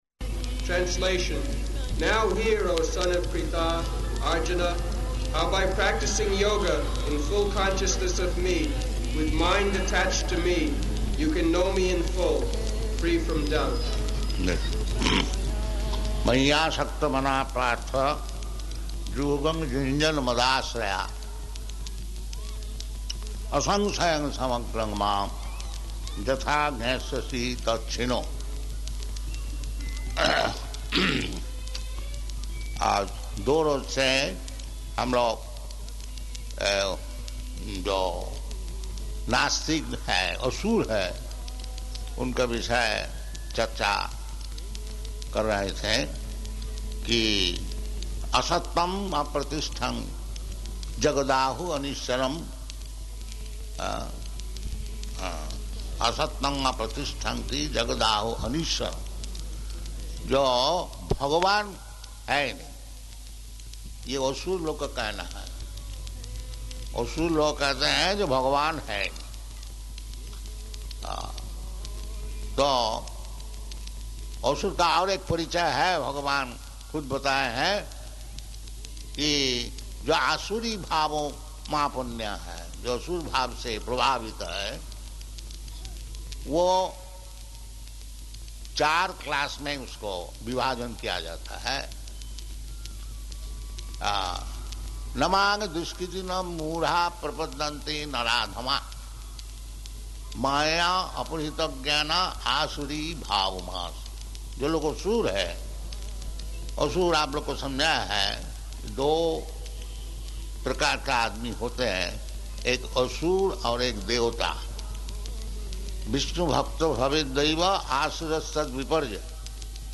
Location: Chandigarh
Prabhupāda: mayy āsakta-manāḥ pārtha yogaṁ yuñjan mad-āśrayaḥ asaṁśayaṁ samagraṁ māṁ yathā jñāsyasi tac chṛṇu [ Bg. 7.1 ] [Hindi] Address in Hindi Press Interview